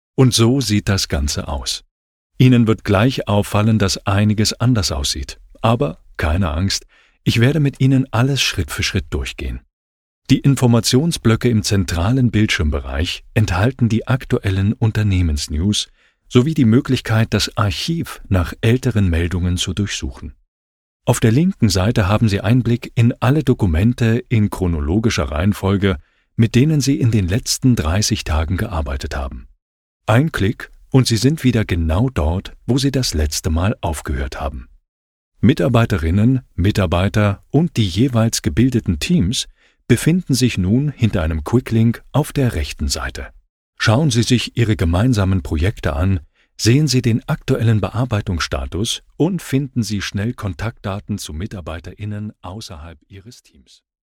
Freundlich